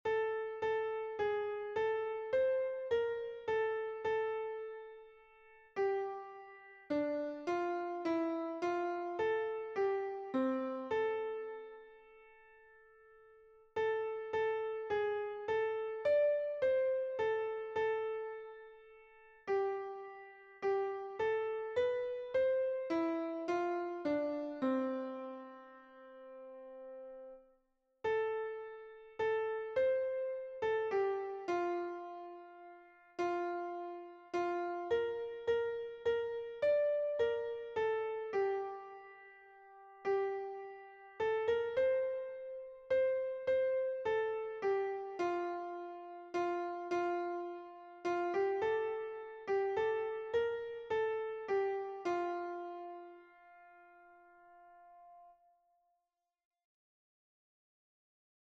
#3034: Turn Your Eyes upon Jesus — Soprano Audio | Mobile Hymns
Words and music by Helen H. Lemmel, 1922 Tune: LEMMEL Key signature: F major (1 fl
Turn_Your_Eyes_Upon_Jesus_soprano.mp3